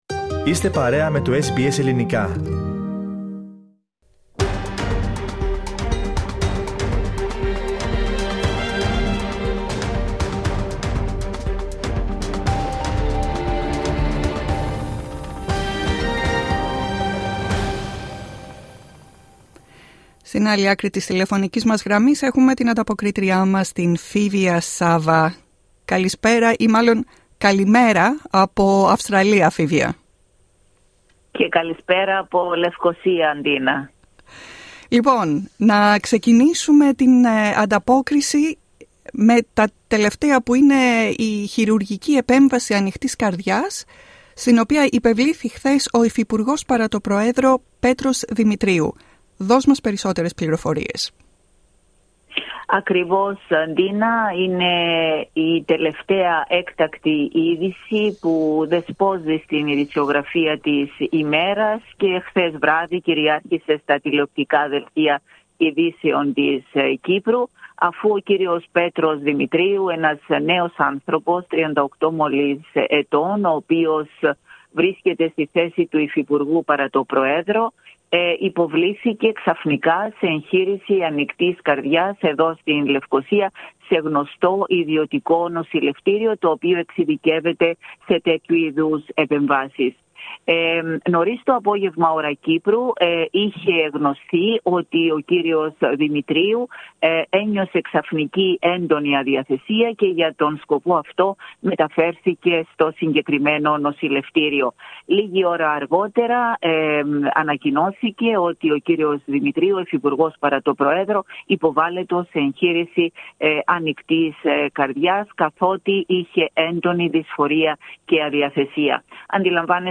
Ακούστε ολόκληρη την ανταπόκρισή μας από την Κύπρο πατώντας στο μέσο της κεντρικής φωτογραφίας.